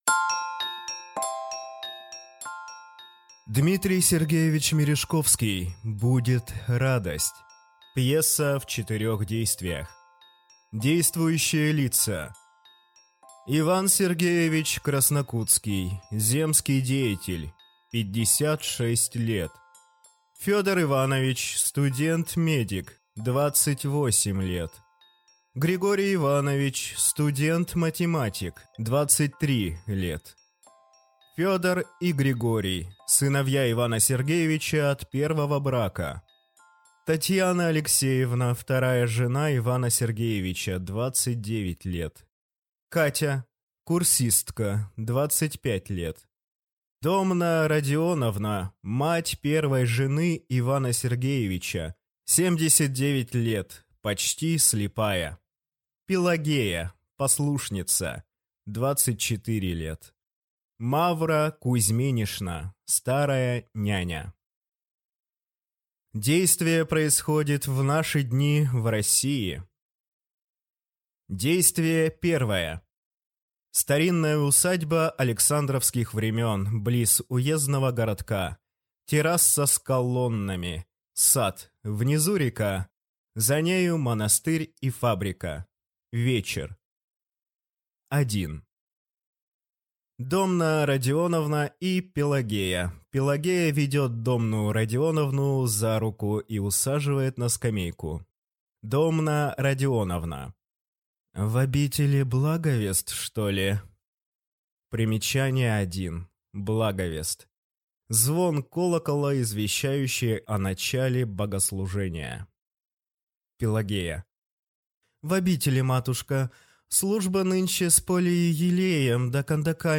Аудиокнига Будет радость | Библиотека аудиокниг